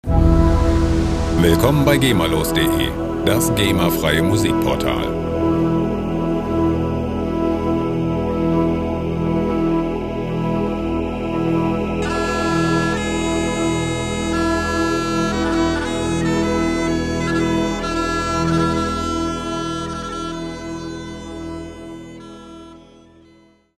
Ambient Loops gemafrei
Musikstil: Ambient Folk
Tempo: 80 bpm